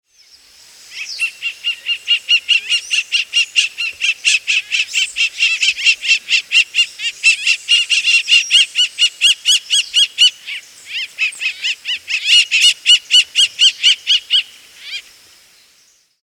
Rupornis magnirostris
Aprecie o canto do
Gavião-carijó
gaviaocarijo.mp3